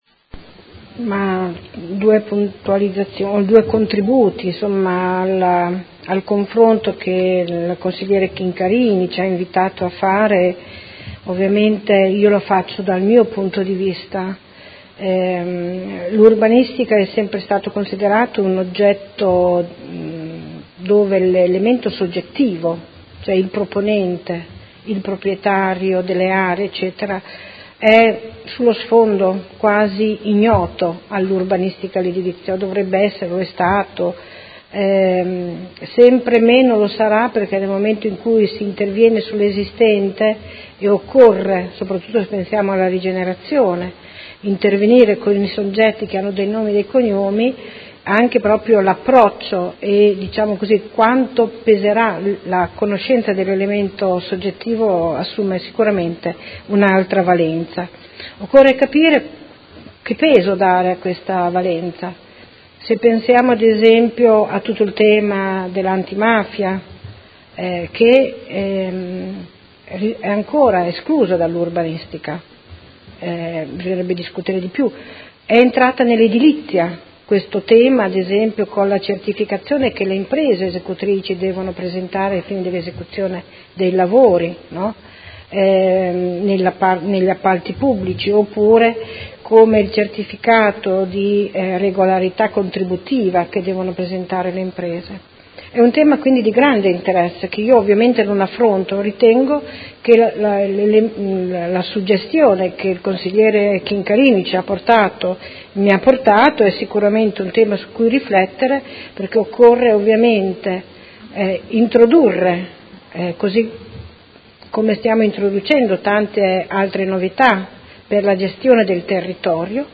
Seduta del 13/07/2017 Conclusioni a dibattito.